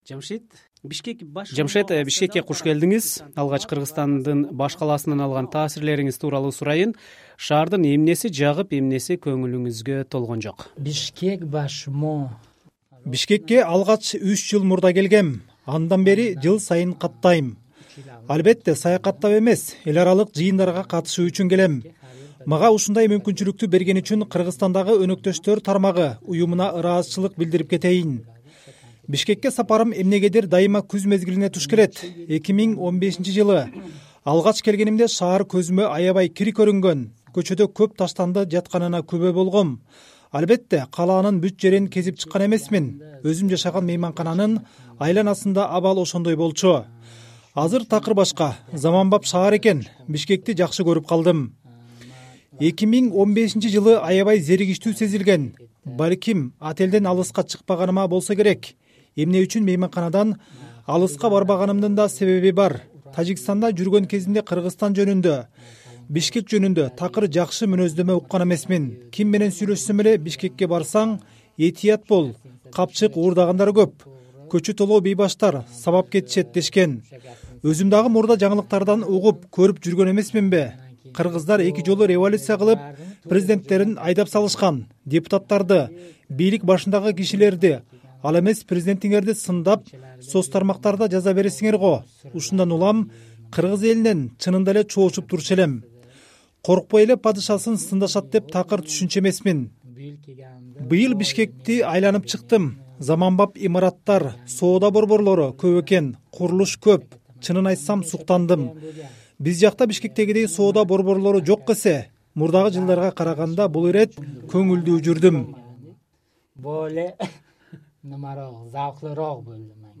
Бишкекти "бейбаштардын шаары" деп элестетип жүргөн тажикстандык дарыгер "Азаттык" радиосуна ой бөлүштү.